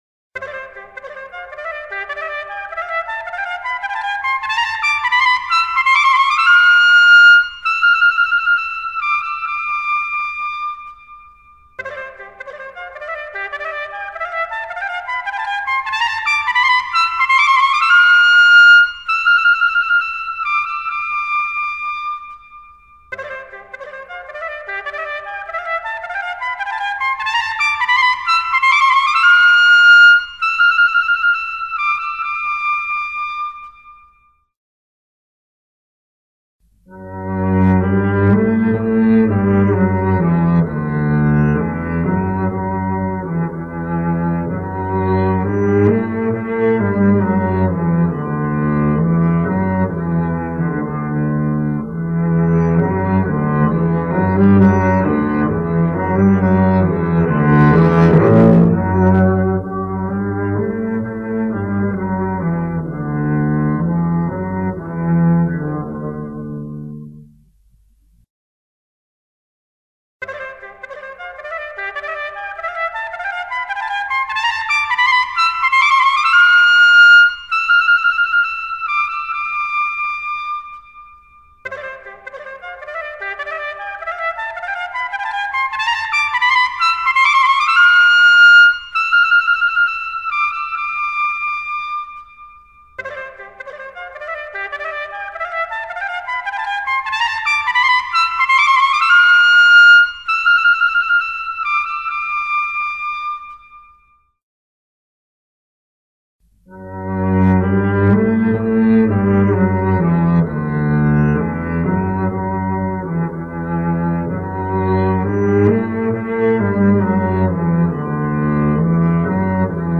Στους ήχους της τρομπέτας η μπάλα περνάει από τον έναν στον άλλο, σε ύψος από το στήθος και πάνω (λεπτοί ήχοι, κίνηση ψηλά). Στους ήχους του κοντραμπάσου (βαθιά “φωνή”) η μπάλα περνάει σε χαμηλό επίπεδο, μέσα από τα πόδια του κάθε παιδιού.
Η εγγραφή 26 που μας οδηγεί στο παιχνίδι, περιλαμβάνει: τρομπέτα – κοντραμπάσο- παύση – τρομπέτα – παύση – κοντραμπάσο.